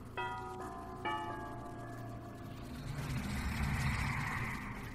Dbd Wraith Bell And Cloak Sound Effect Download: Instant Soundboard Button